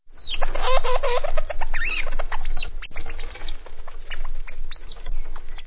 Leurs cris :
- une indescriptible bataille vocale lorsqu'ils se nourrissent
2 octodons se disputant de la nourriture (.wav)